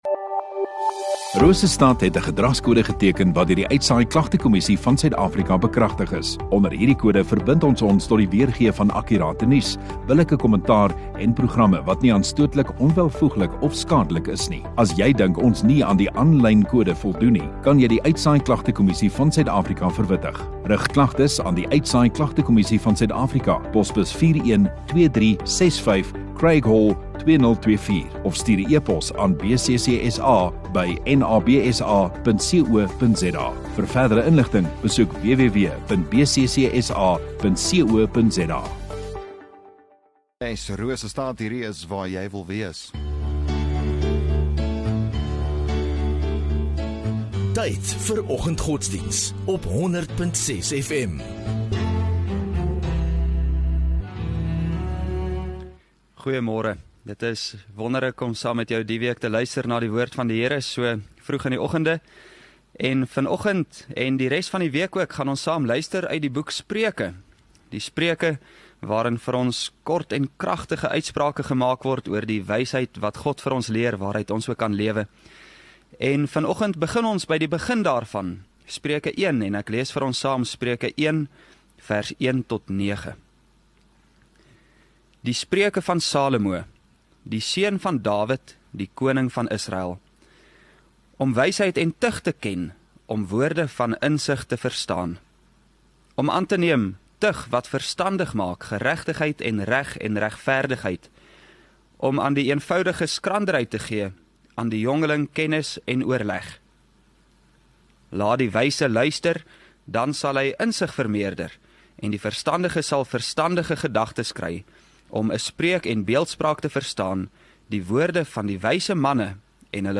4 Aug Maandag Oggenddiens